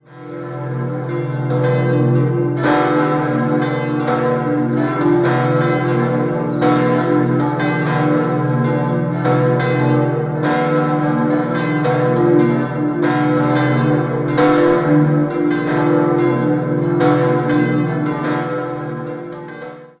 5-stimmiges Geläut: h°-d'-e'-g'-h'(+) Alle Glocken wurden 1653 von Schelchshorn in Regensburg gegossen.